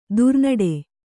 ♪ durnaḍe